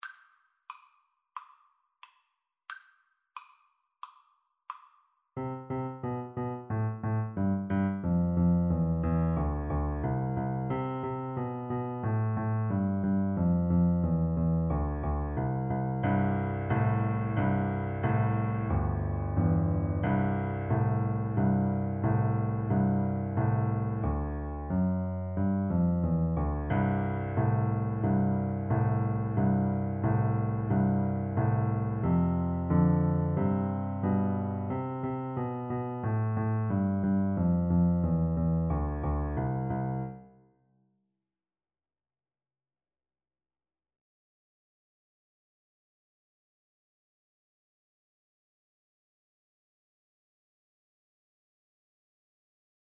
A beginners piece with a rock-like descending bass line.
March-like = 90
Pop (View more Pop French Horn Music)